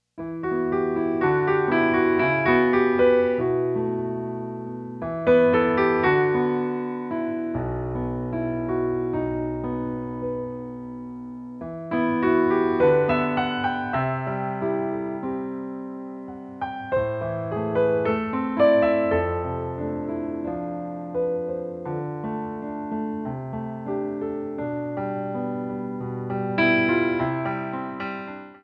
Piano Stylings